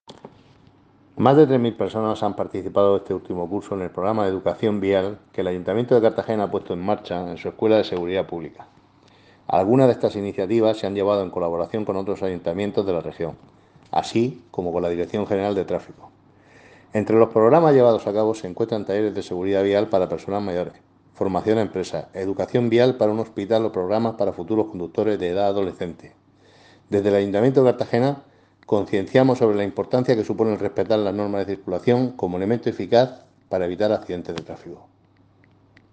Enlace a Declaraciones del edil José Ramón Llorca.